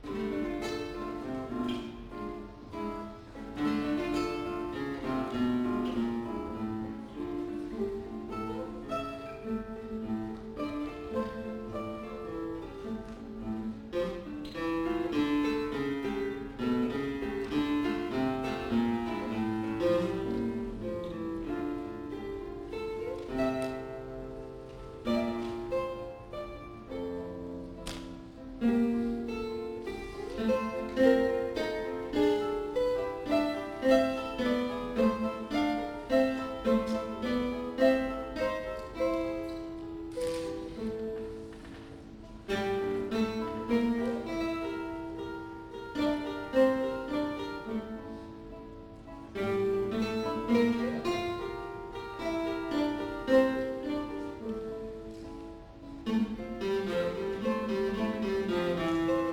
1992年12月18日　於 市民プラザ アンサンブルホール
三重奏
3_trio_s.mp3